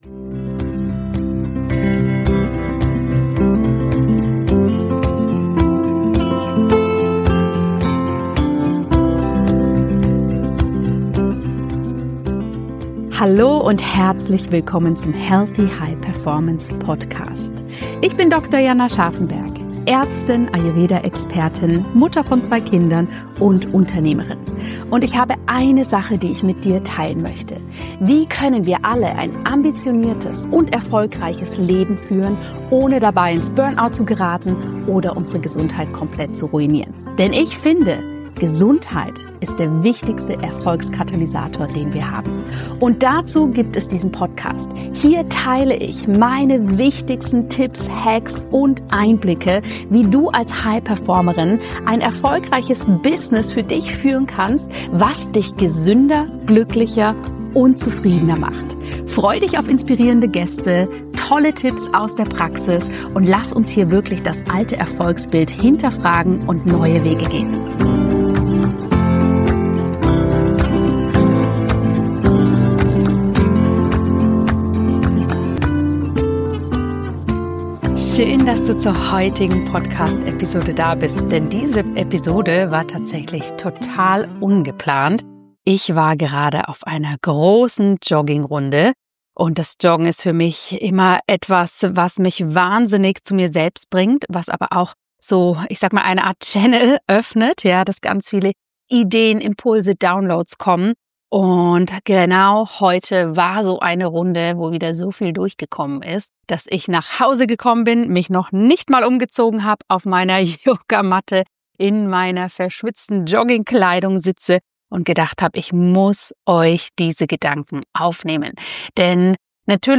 Podcast #374 -In dieser Episode nehme ich dich mit auf meine Laufrunde in der Natur – dorthin, wo mein größter Channel aufgeht.